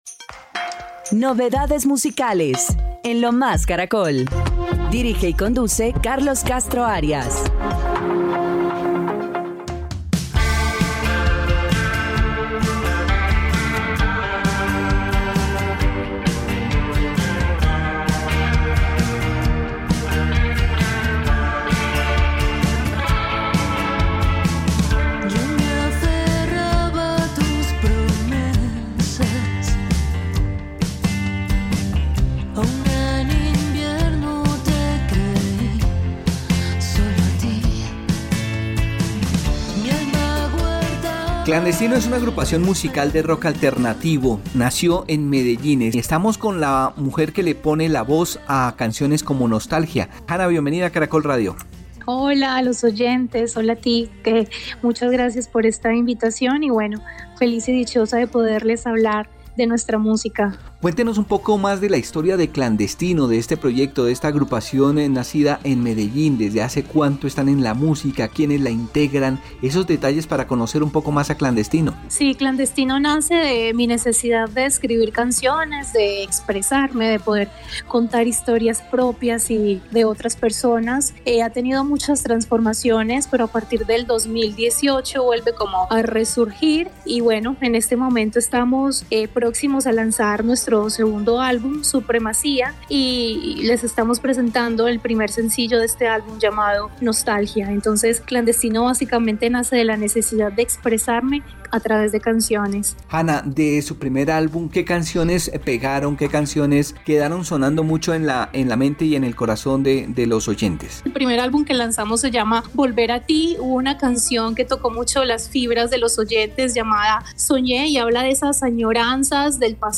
bajo
batería